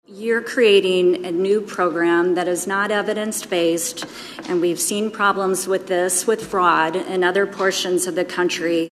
Senator Janet Petersen, a Democrat from Des Moines, says the state should spend more money to keep labor and delivery departments in hospitals open rather than fund crisis pregnancy centers.